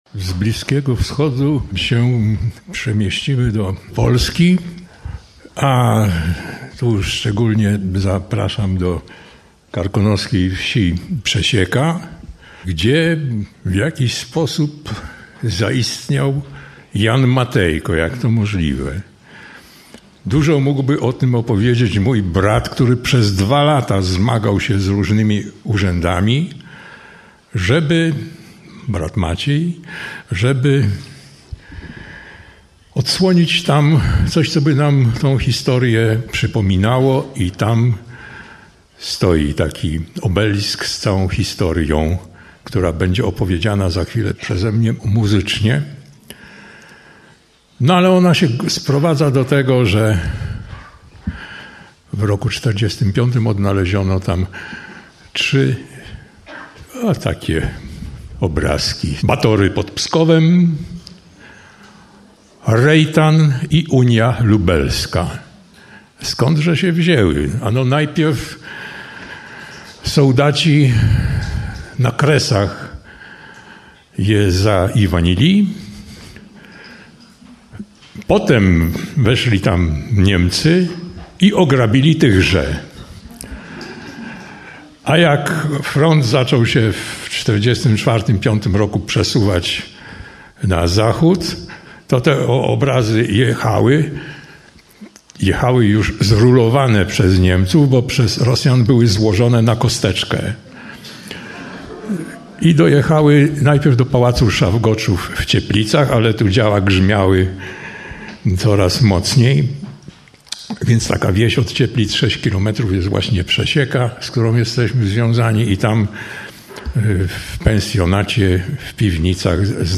ballady